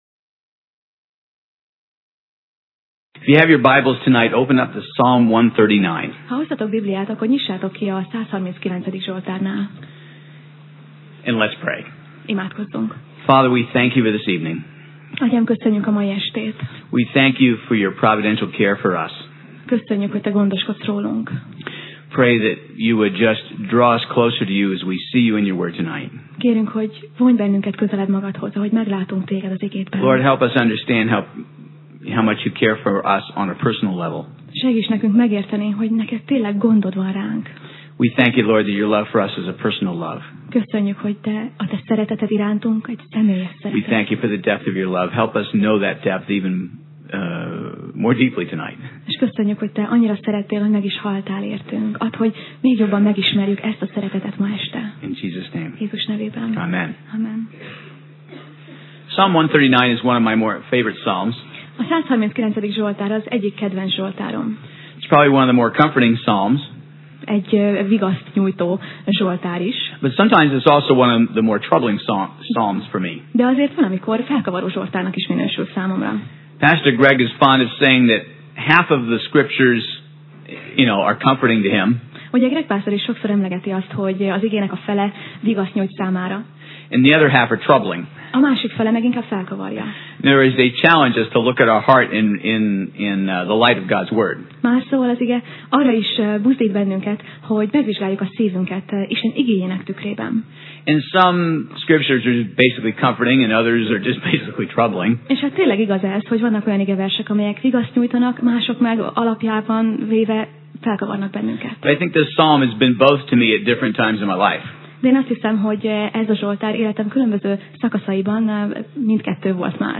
Sorozat: Tematikus tanítás Passage: Zsoltárok (Psalm) 139 Alkalom: Szerda Este